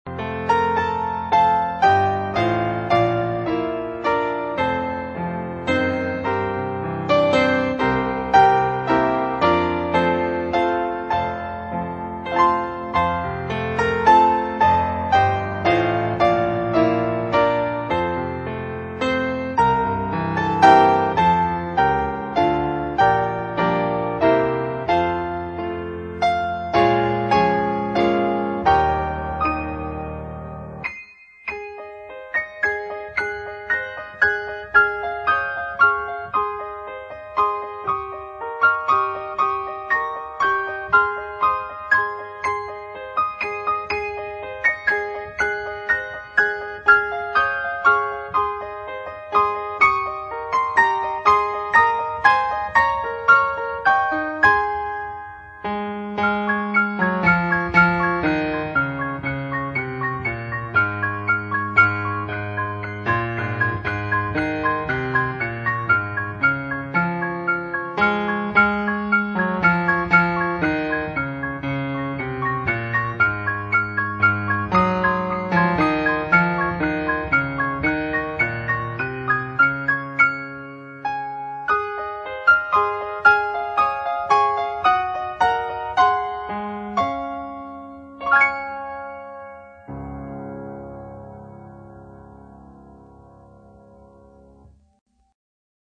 Background music in a new window